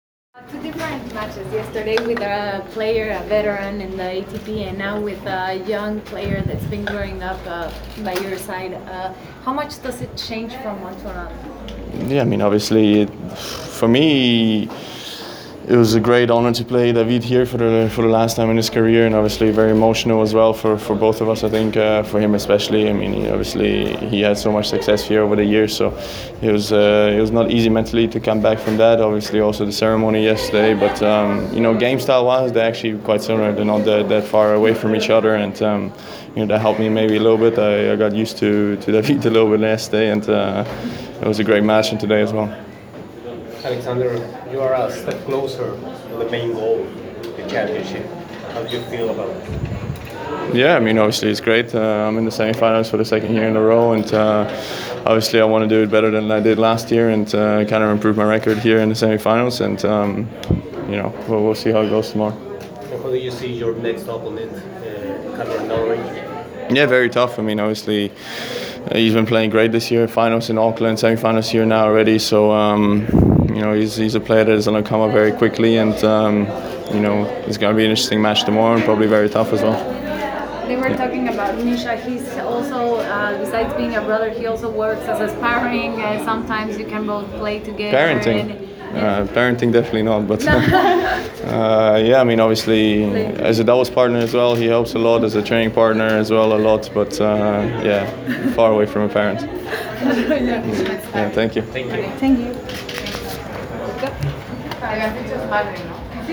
Press Conference – Alexander Zverev (28/02/2019)